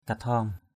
/ka-tʱɔ:m/ (cv.) thaom _E> (d.) bao, bị = enveloppe. kathaom ndaw k_E> Q| bao kiếm = fourreau de l’épée. kathaom klai k_E> =k* bị đái = bourses (scrotum). mablah kathaom urang...